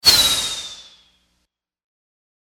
/ F｜演出・アニメ・心理 / F-30 ｜Magic 魔法・特殊効果
レーザー光線 1発 01ヒューン